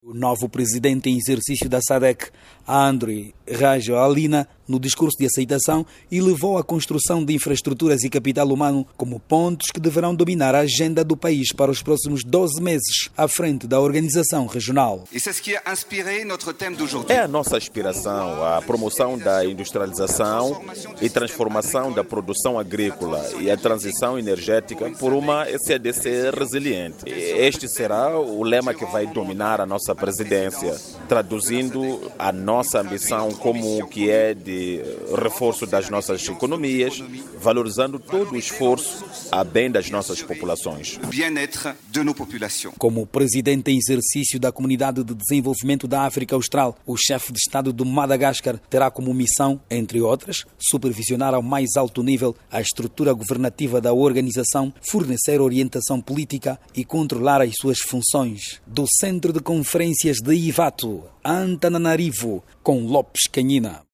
a partir da capital do Madagáscar